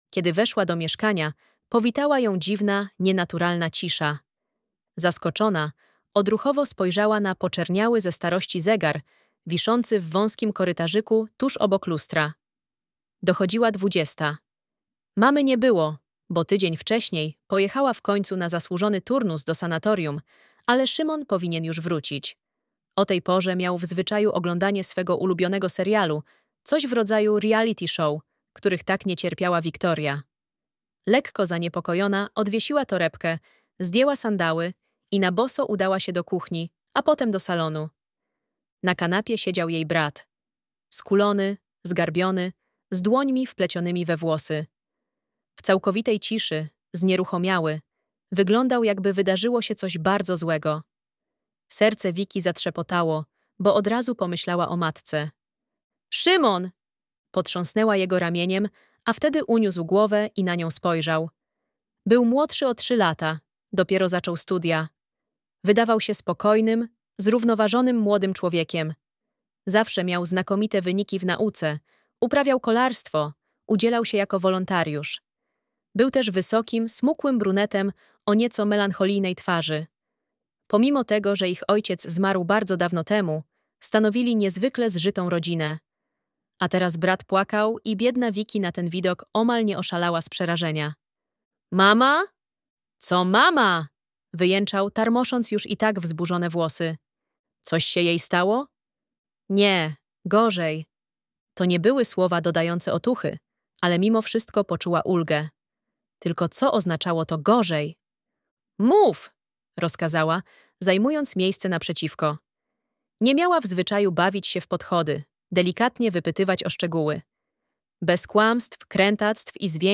Dług - Agnieszka Kowalska-Bojar - audiobook